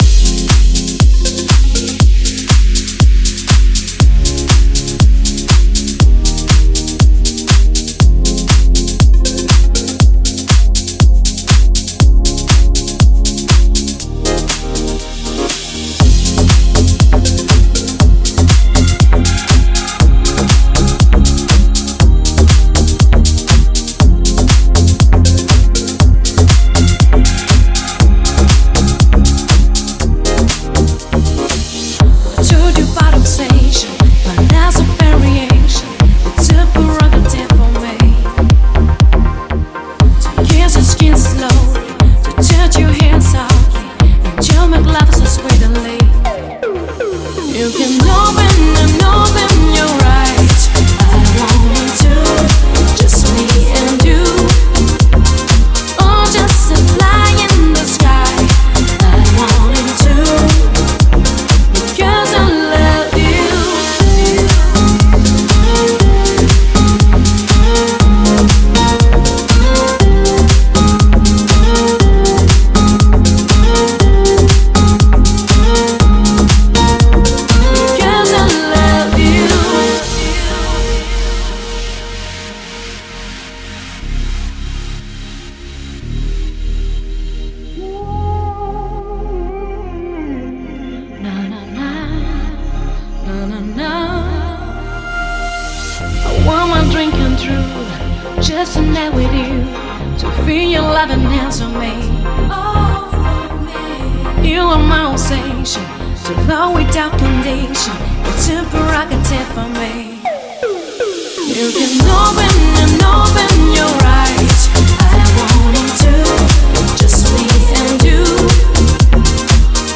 Стиль: Deep House